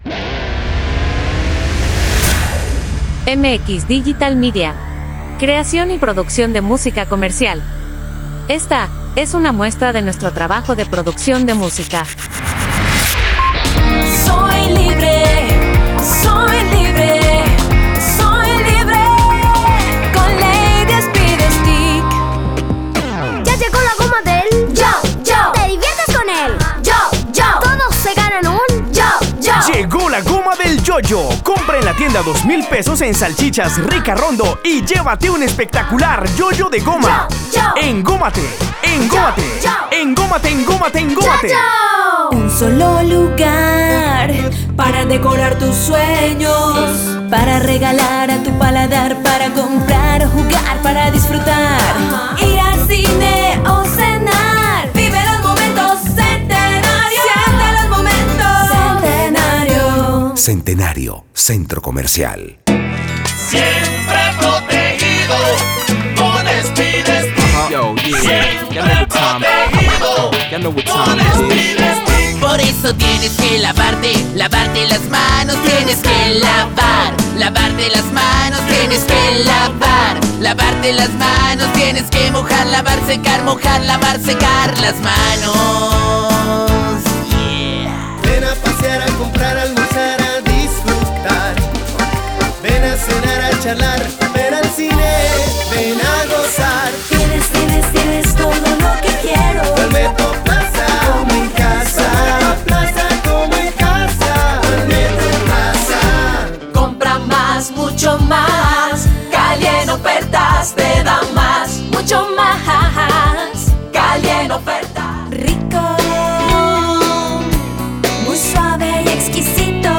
Jingles
Rítmos Colombianos